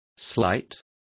Also find out how pequena is pronounced correctly.